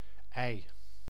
Geordie and Northumberland, when not final or before a voiced fricative: [ɛɪ~əɪ]